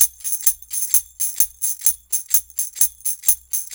128-TAMB1.wav